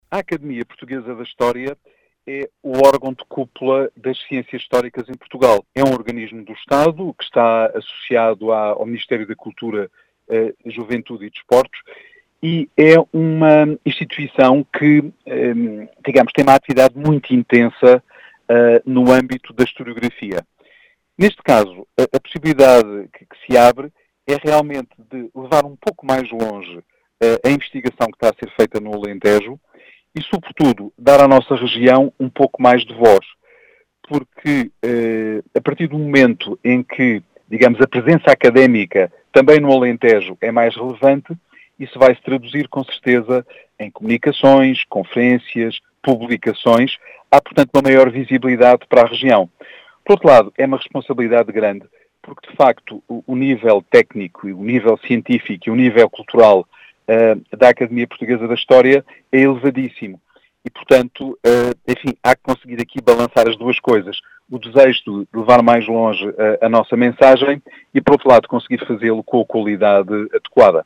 Em declarações á Rádio Vidigueira